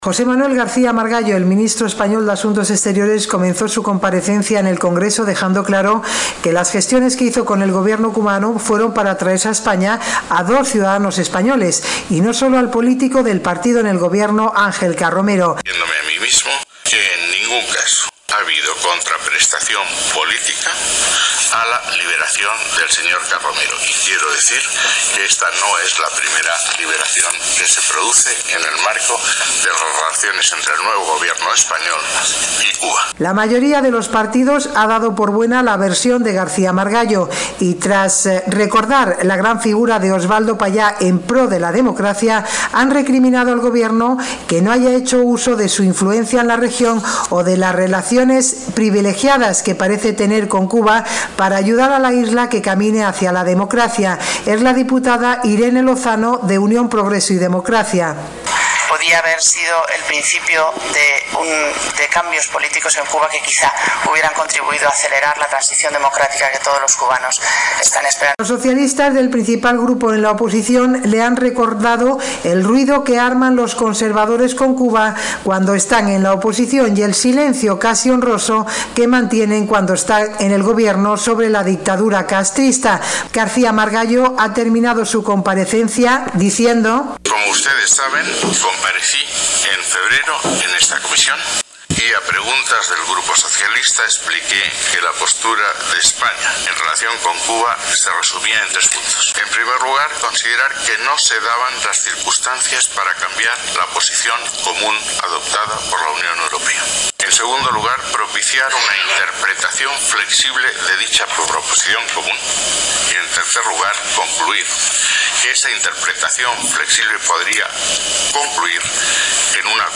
El jefe de la diplomacia española, José Manuel García-Margallo, intervino ante el Congreso español sobre el tema de la liberación de Ángel Carromero.